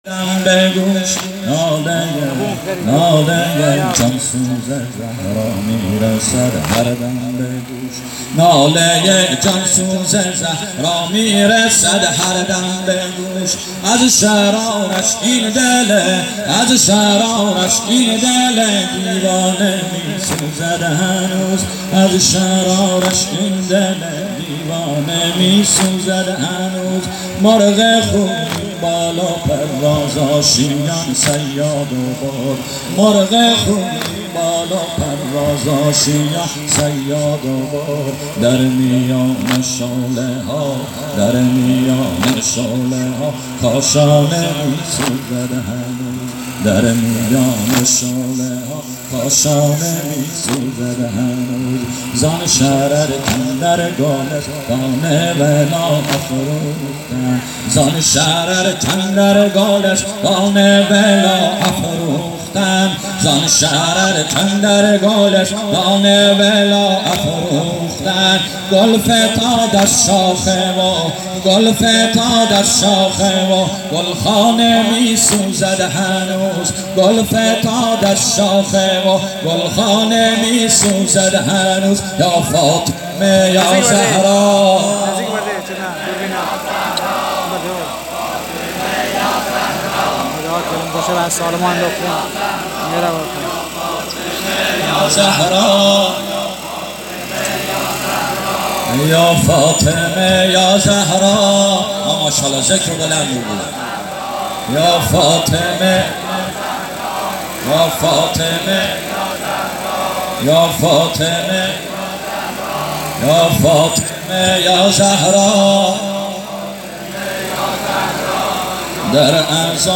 اجتماع عزاداران فاطمی ظهر شهادت حضرت زهرا۹۷هیئت میثاق الحسین علیه السلام سیستان.نیمروز.
مداحی